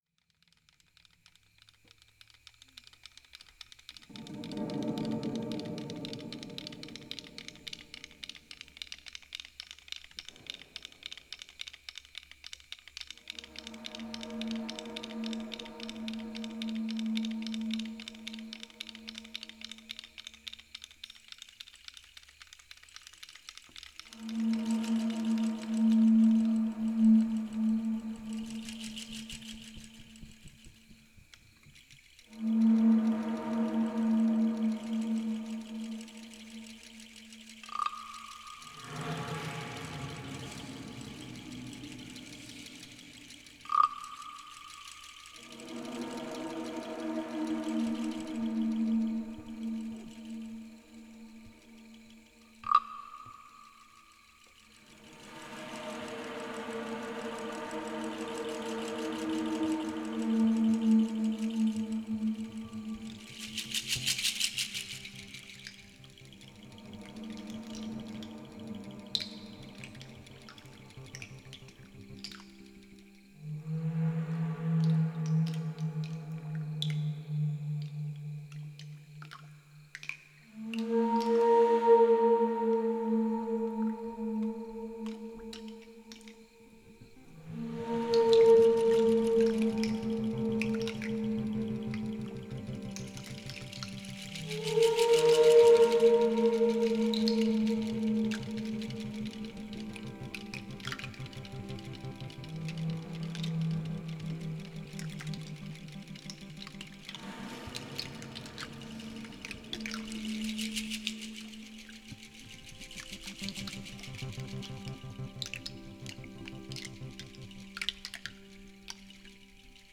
A lush, atmospheric live recording
Mouthbow, Conch Shell, Windpipes etc. with
Dobro guitar and voice
Moonbells & Waterbell
drums & percussion